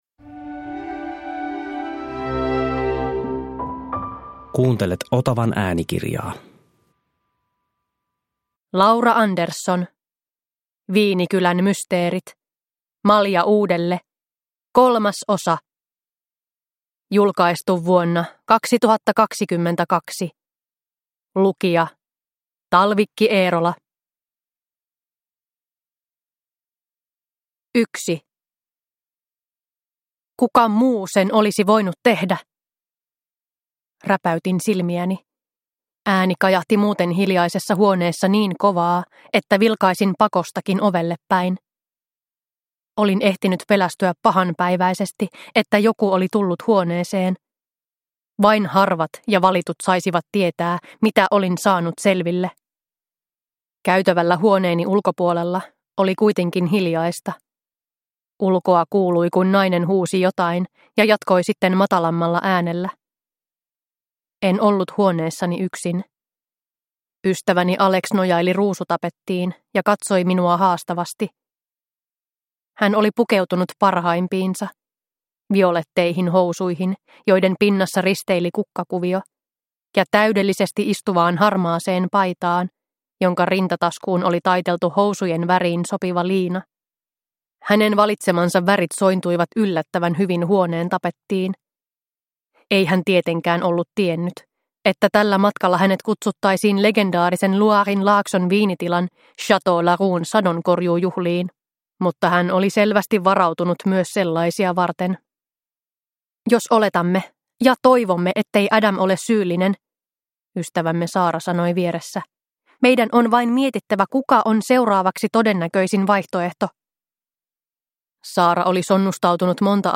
Malja uudelle 3 – Ljudbok – Laddas ner